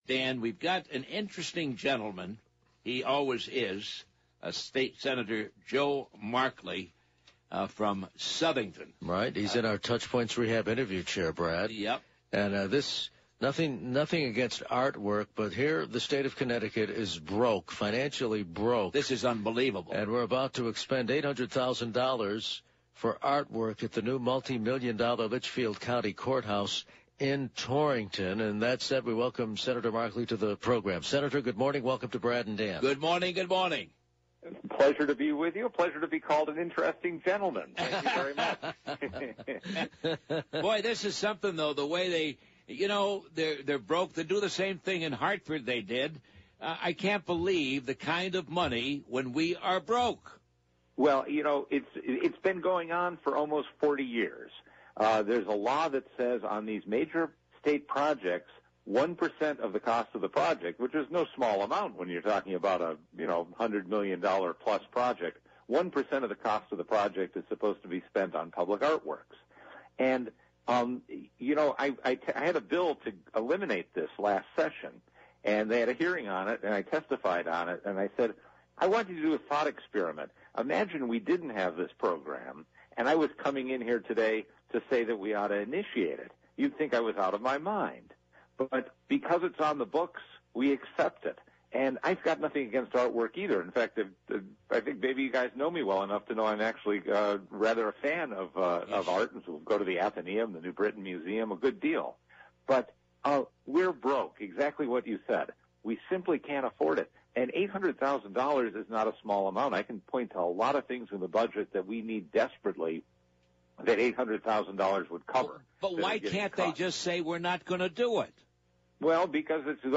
In a wide-ranging interview, Connecticut state Sen. Joe Markley is predicting that the state will have a budget by Labor Day, though it may include Democrat-supported tax hikes.